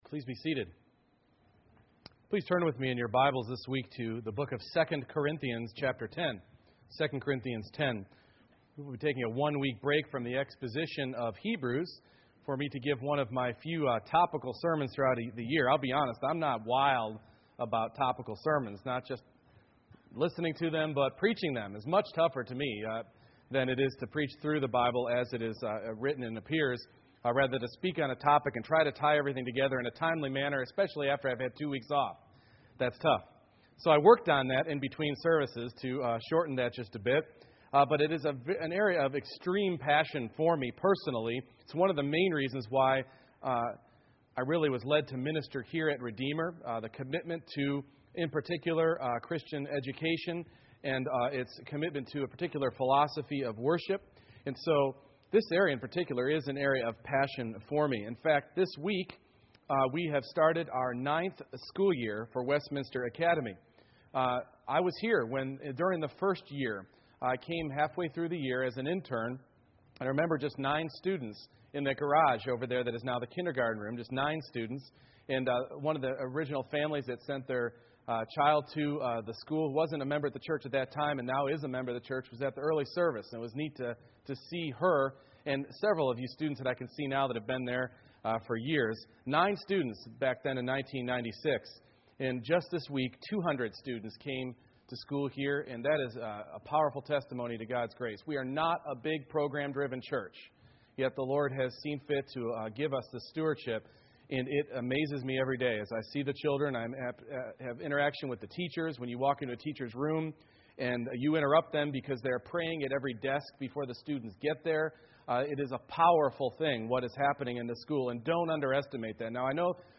Redeemer Vision Service Type: Morning Worship What is a world and life view?